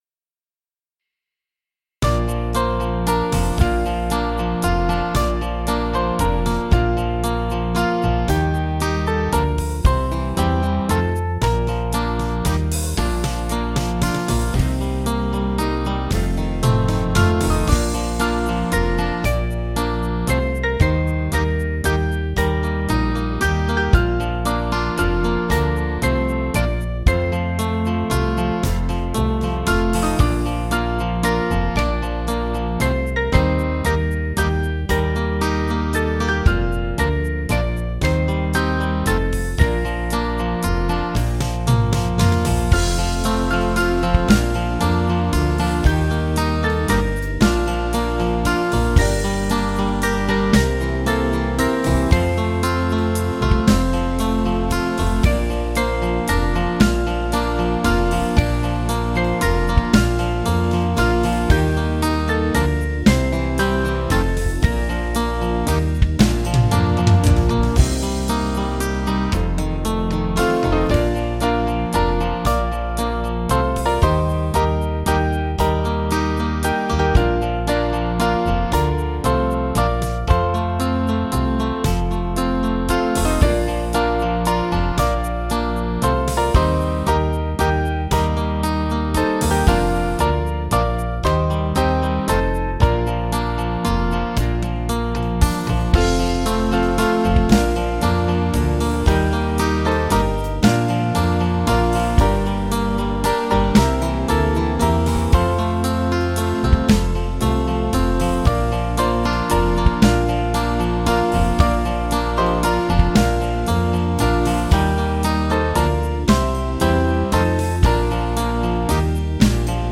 Liturgical Music
Small Band